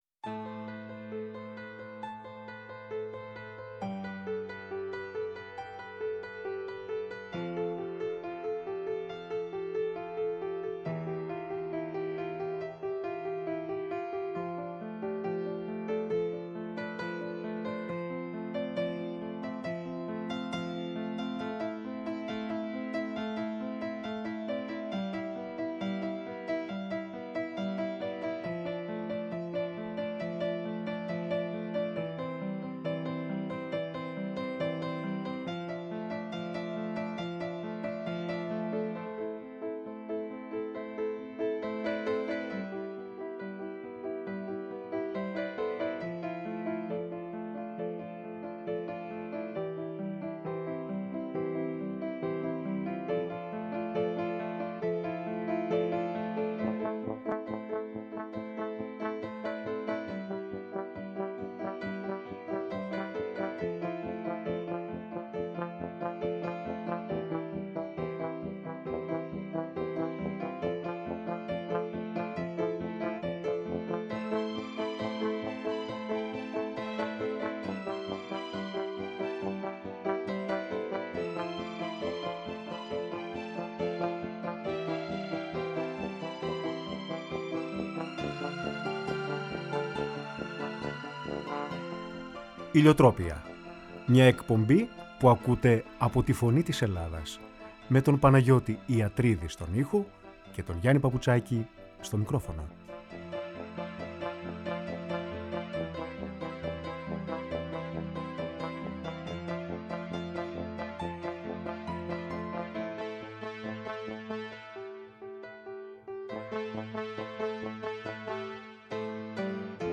Η ΦΩΝΗ ΤΗΣ ΕΛΛΑΔΑΣ Ηλιοτροπια Συνεντεύξεις